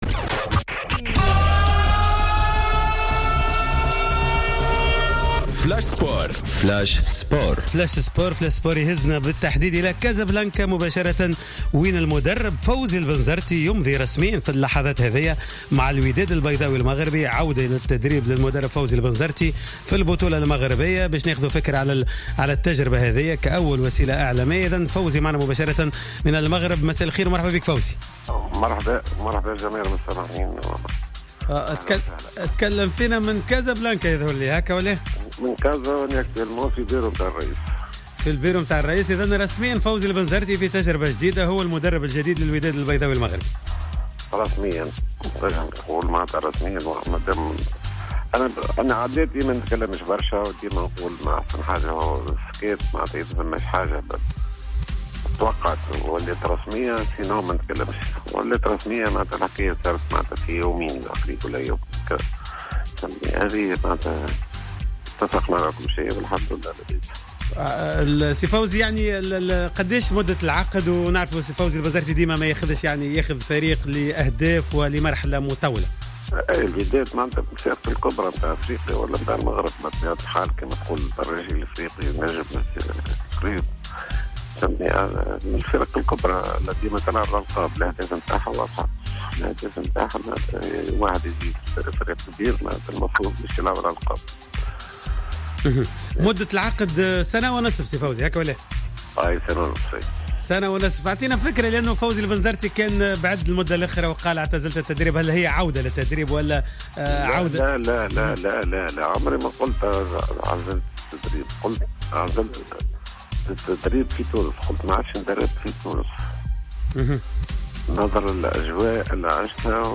المدرب فوزي البنزرتي